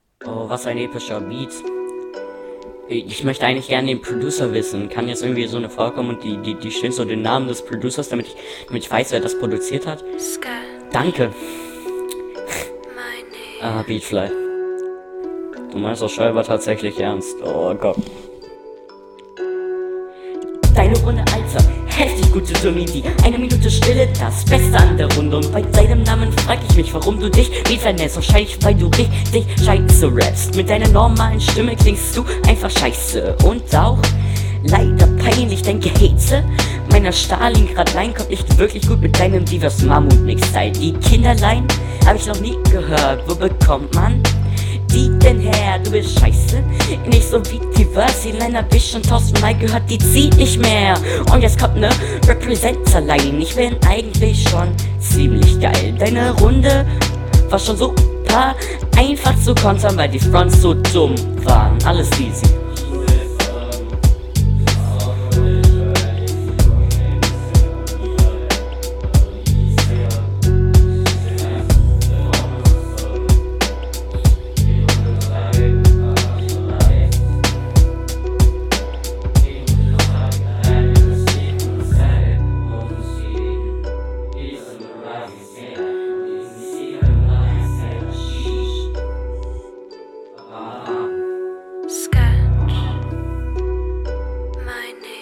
Okay Stimme gepitcht.